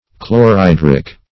Search Result for " chlorhydric" : The Collaborative International Dictionary of English v.0.48: Chlorhydric \Chlor`hy"dric\, a. [Chlorine + hydrogen + -ic.]